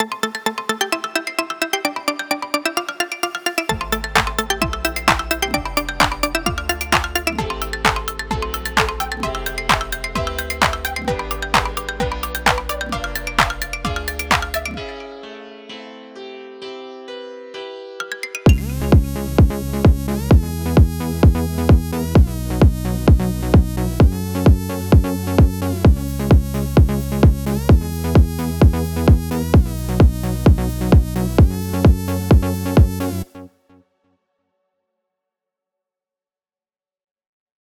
ניסיתי טיפה להוסיף לזה ויריאציות, אז זה באמצע עבודה, אל תתיחסו לפסנתר רק מה דעתכם על הסיידצן, זה מוגזם?
טרק לייצוא חדש גדול עם סיידצן.wav